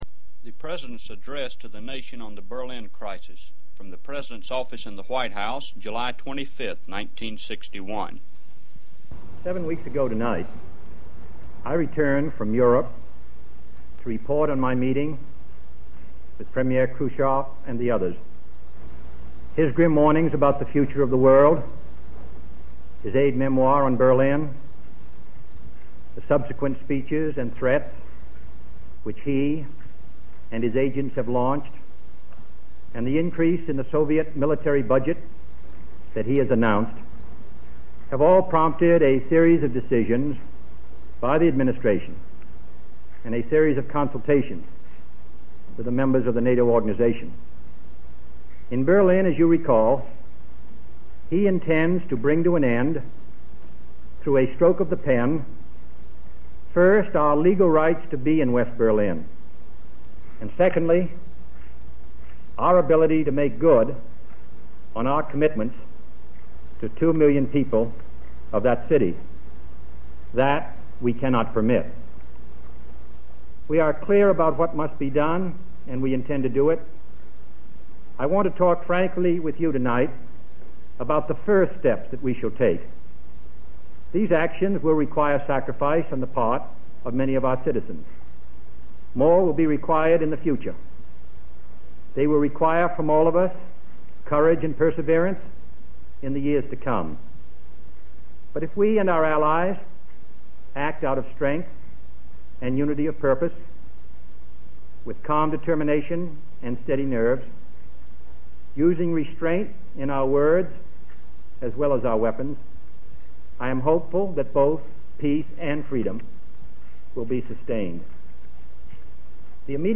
Video Audio John F. Kennedy's report to the Nation on Berlin, 25th July 1961.
Speech by John F. Kennedy on the Berlin Crisis (Washington, 25 July 1961)